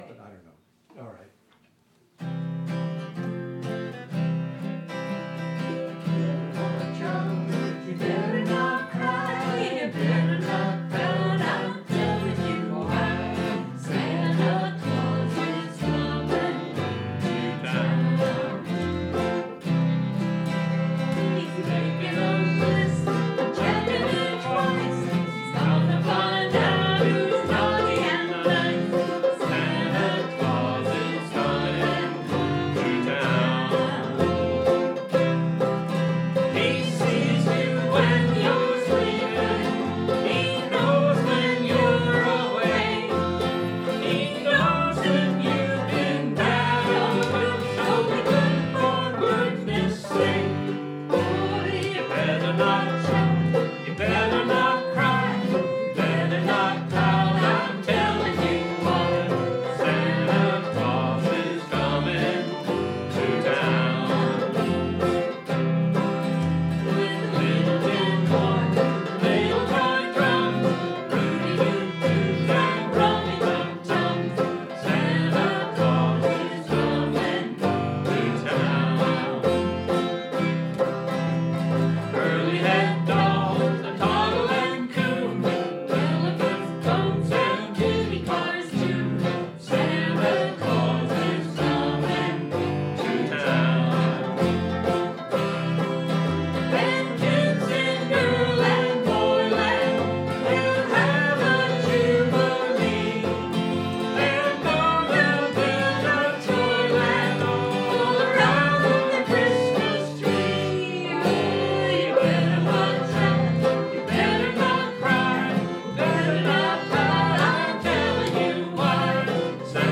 Practice track